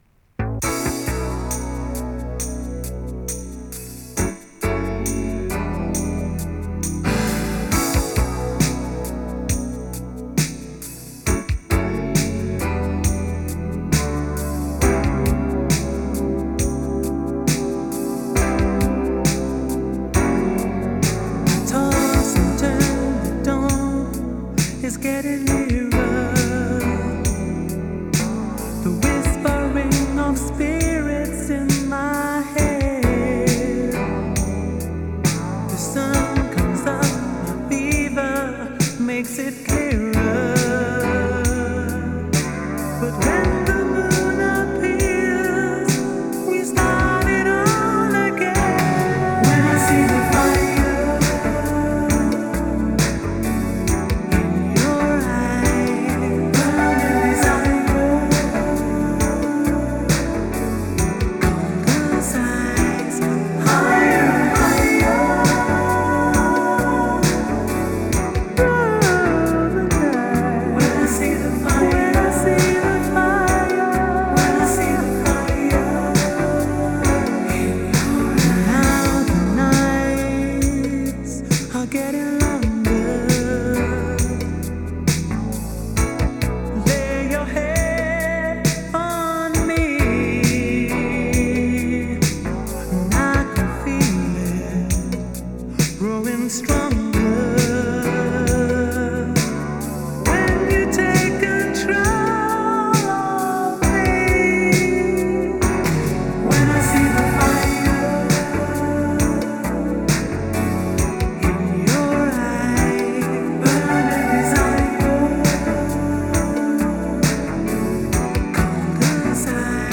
хоть и Vinyl Rip , но песочка не слышно!